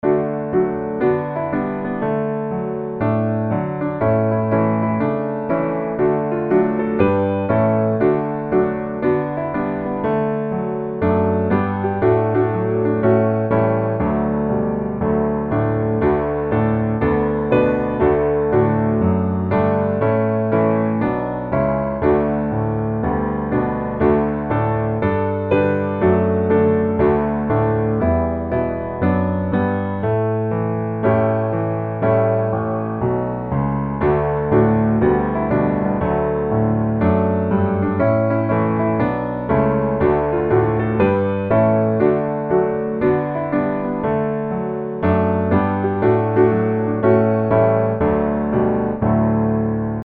Gospel
D大調